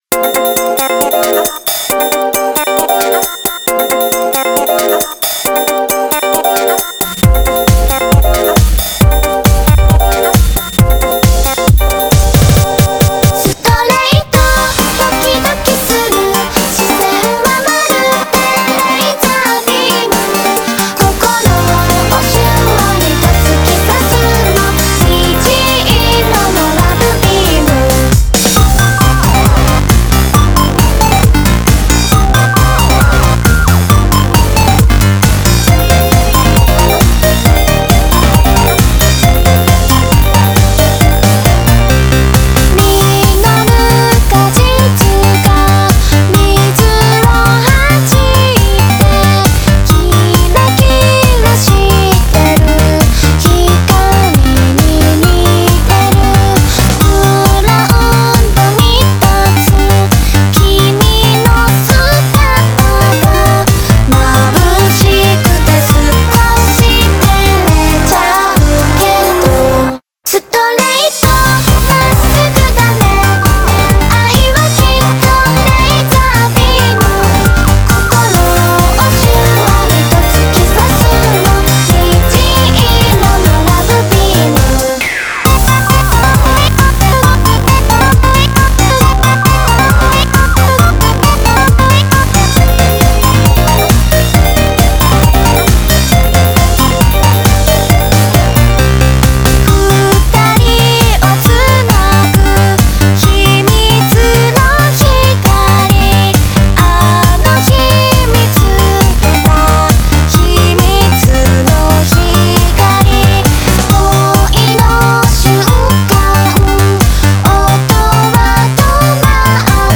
BPM135
Audio QualityPerfect (High Quality)